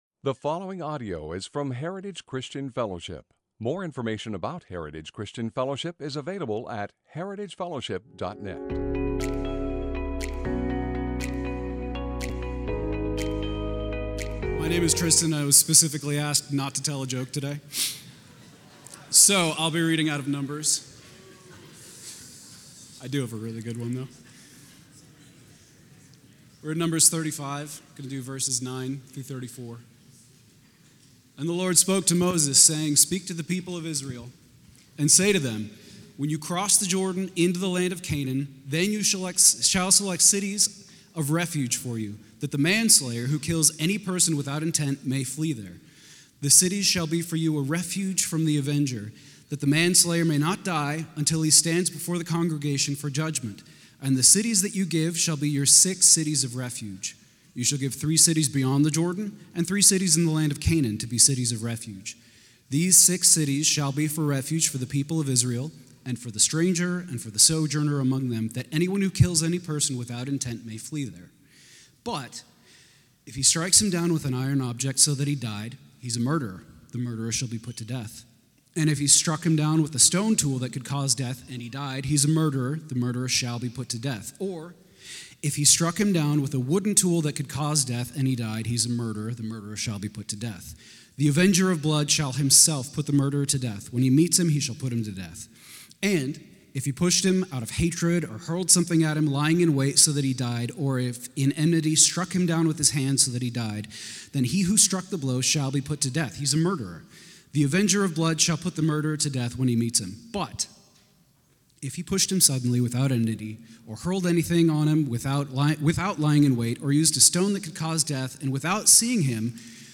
Sermons - Heritage Christian Fellowship | Of Medford, OR